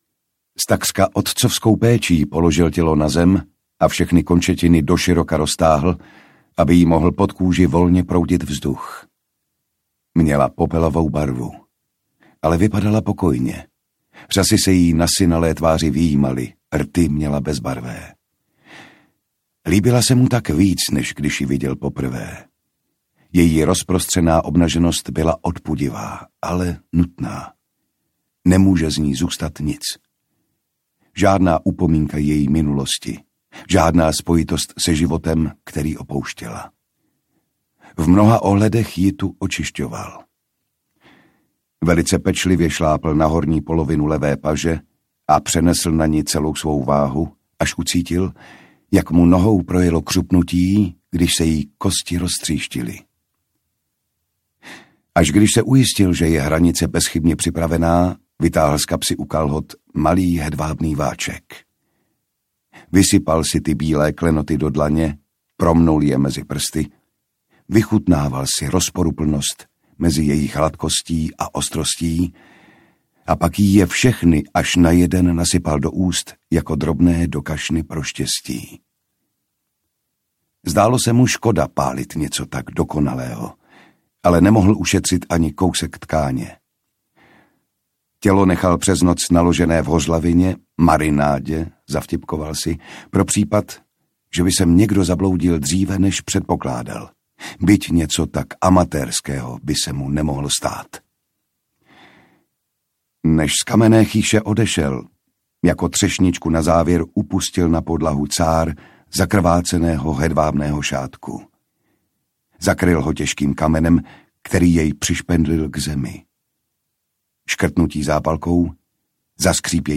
Dokonalé stopy audiokniha
Ukázka z knihy